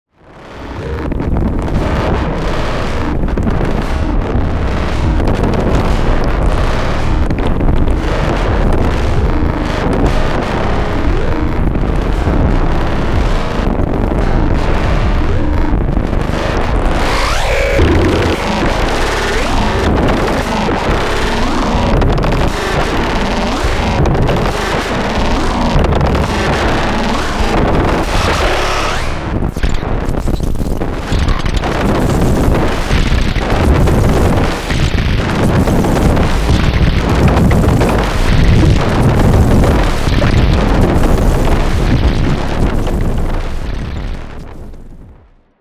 And a more agitated one where it modulates Strega and DFAM
…because mayhem of course .